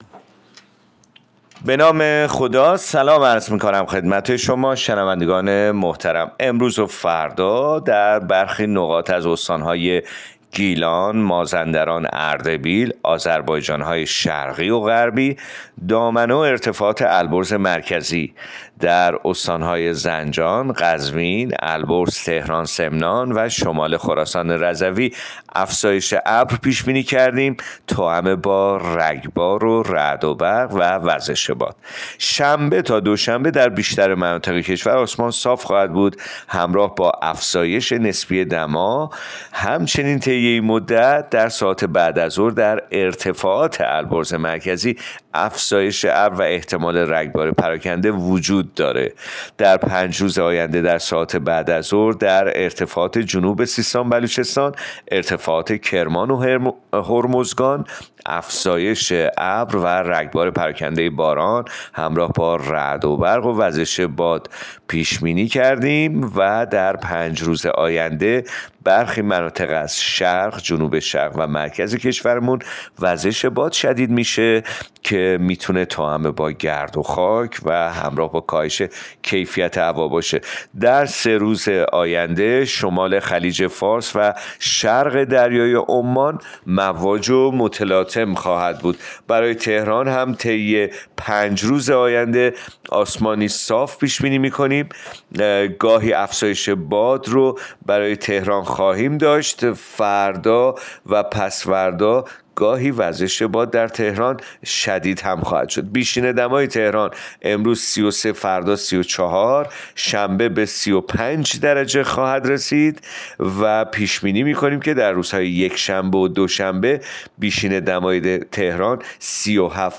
گزارش رادیو اینترنتی پایگاه‌ خبری از آخرین وضعیت آب‌وهوای ۲۲ خرداد؛